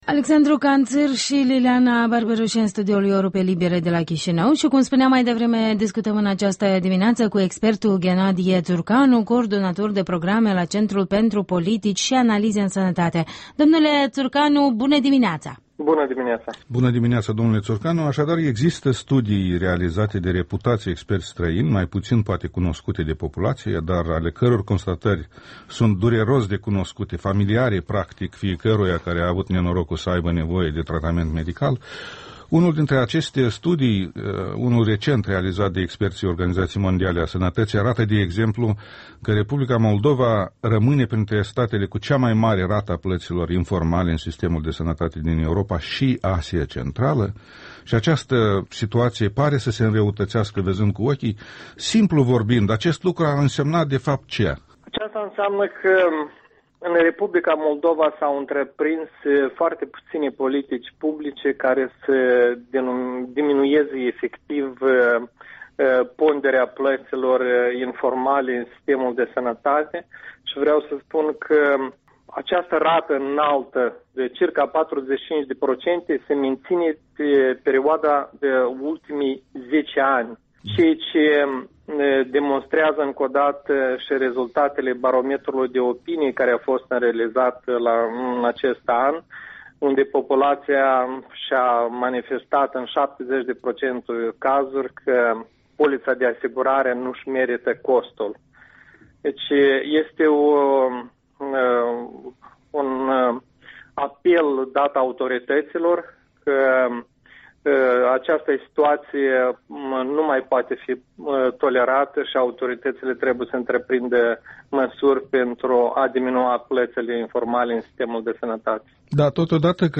Interviul dimineții cu un cordonator de programe la Centrul pentru Politici și Analize în Sănătate.